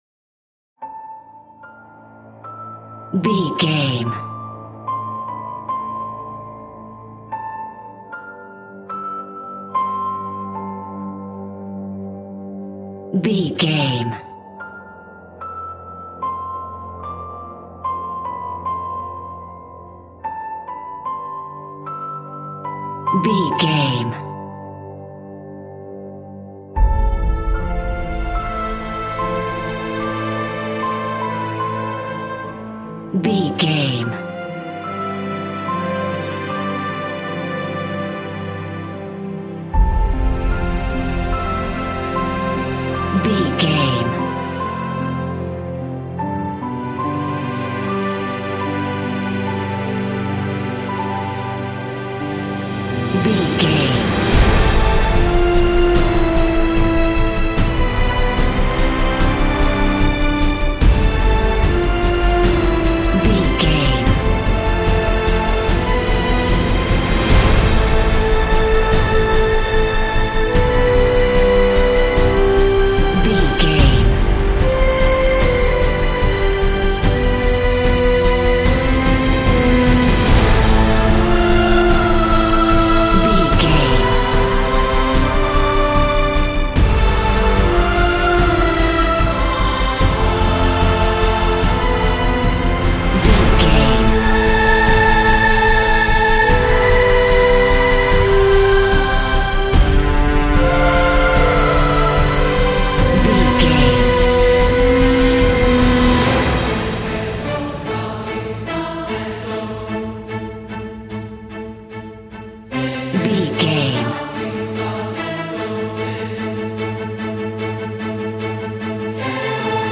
Epic / Action
In-crescendo
Ionian/Major
energetic
horns
percussion
orchestra
piano